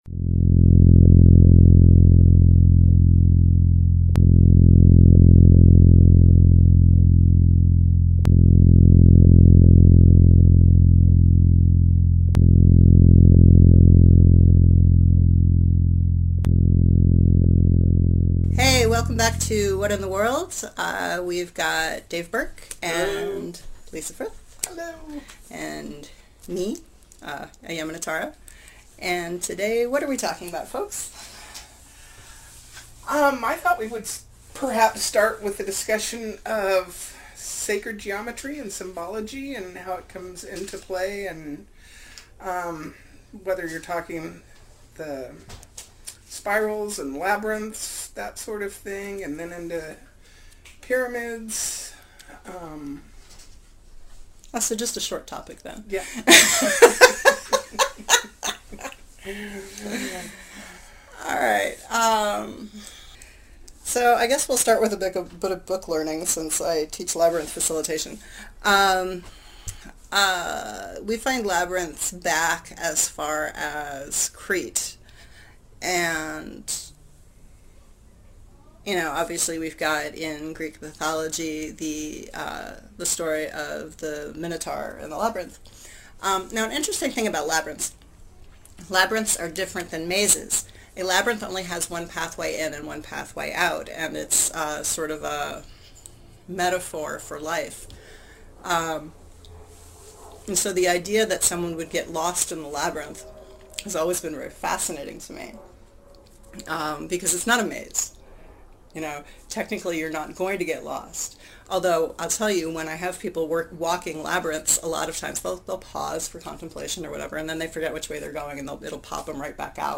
Intro and Outro are a recording of a Keppler star, courtesy of NASA